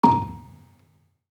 Gambang-A#4-f.wav